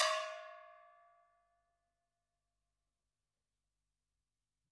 描述：大金属罐，用鼓棒的各种敲击声用一个EV RE20和两个压缩的omni麦克风录制的立体声大量的房间声音
标签： 大锡 工业 金属 金属
声道立体声